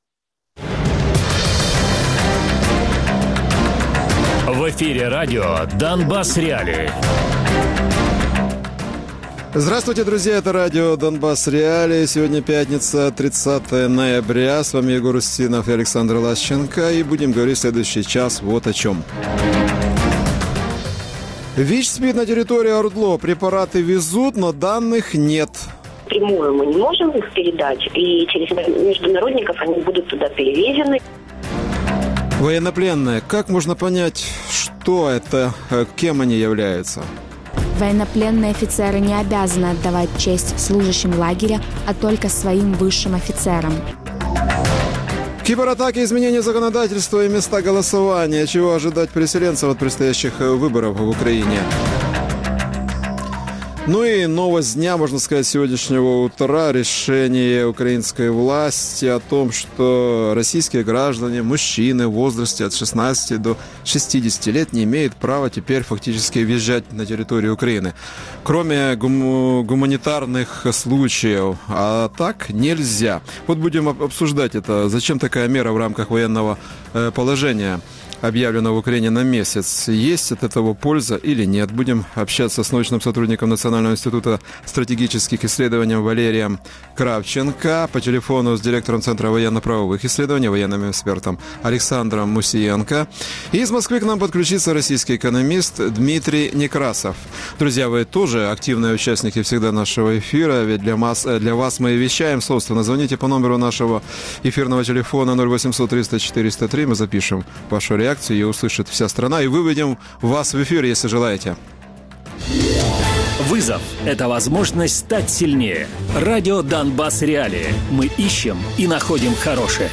Радіопрограма